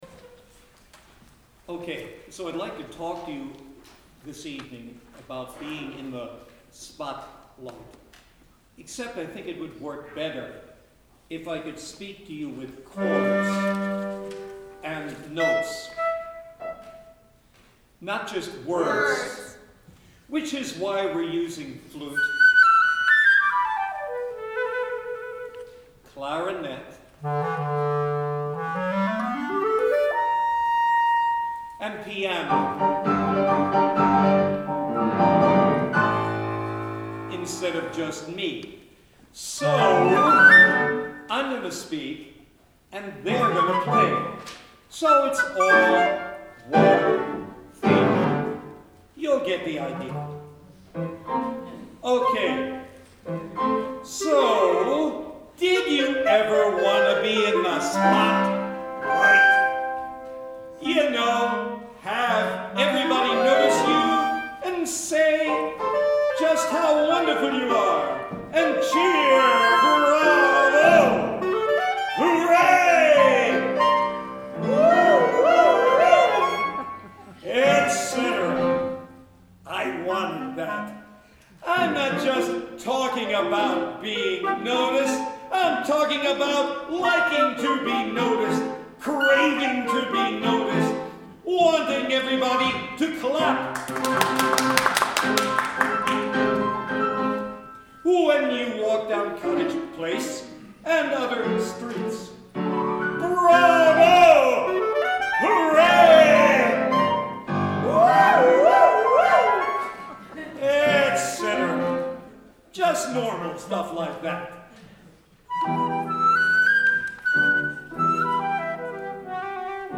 So I'm gonna speak and they're gonna play.